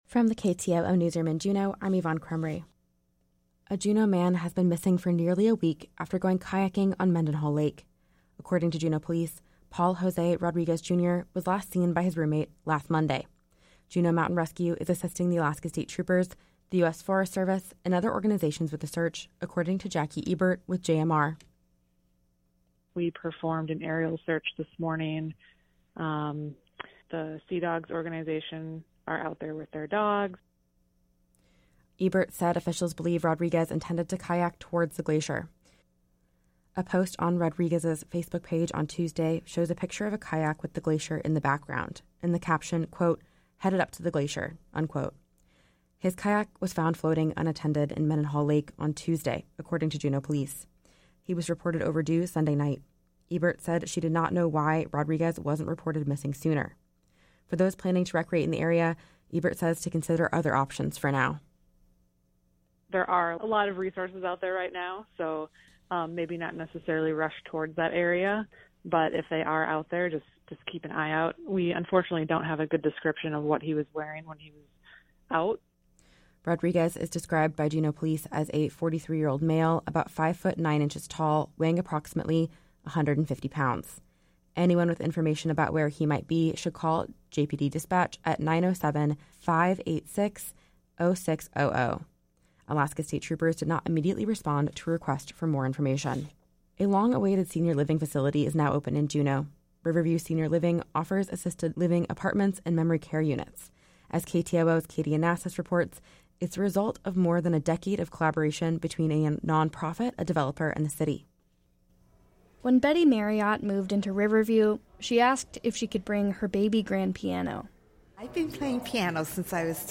Newscast – Monday, July 17, 2023